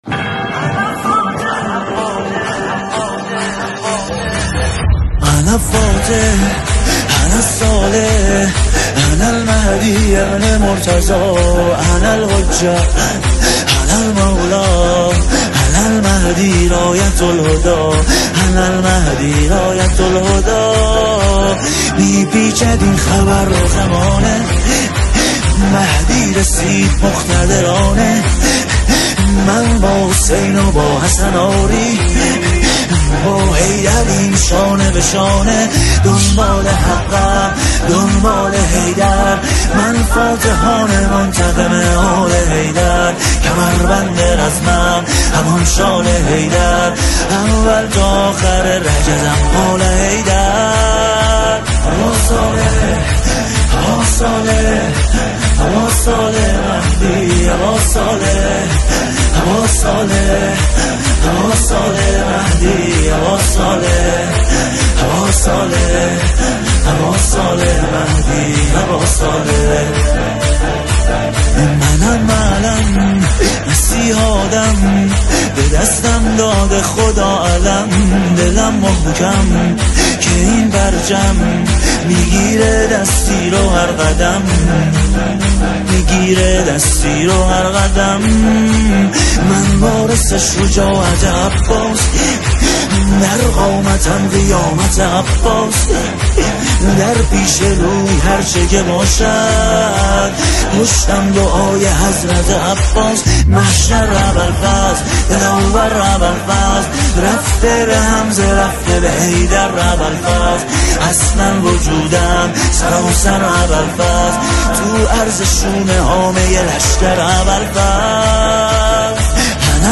نماهنگ مهدوی